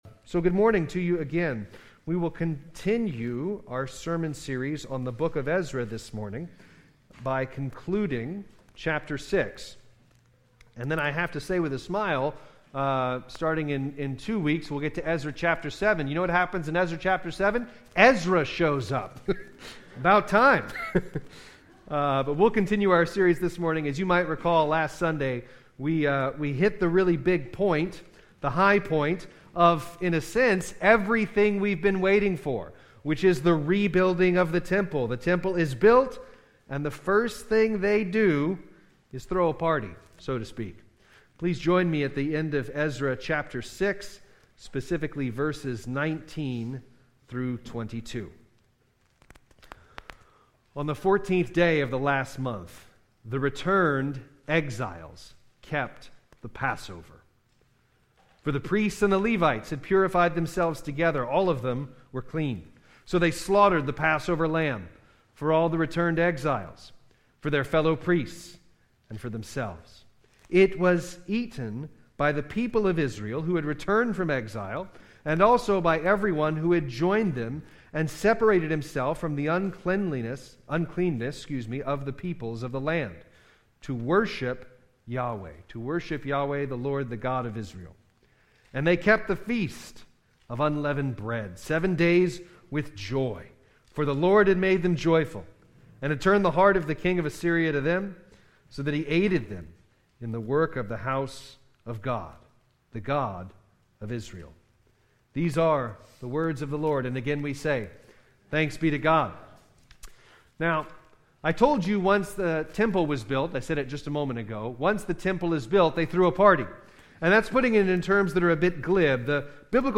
Sermons by Grace Presbyterian Church - Alexandria, LA